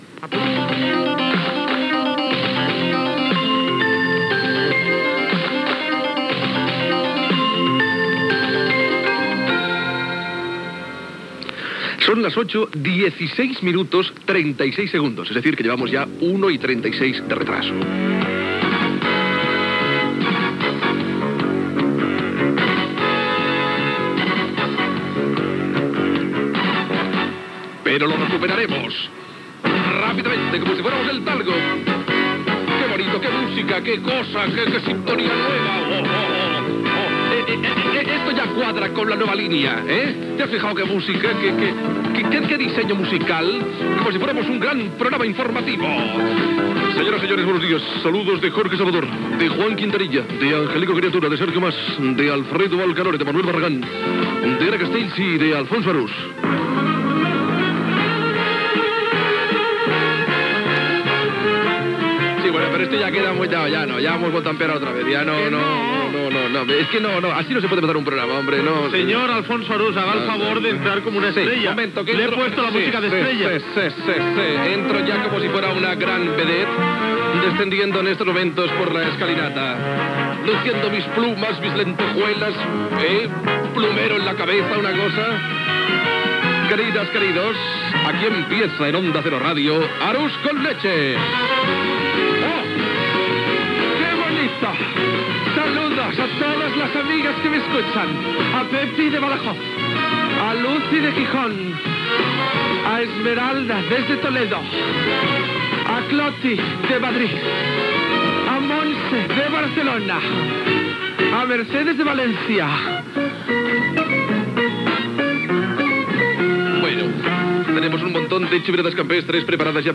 Sintonia de la cadena, hora, presentació del primer programa, amb els noms de l'equip, en el dia d'inauguració de l'emissora
Entreteniment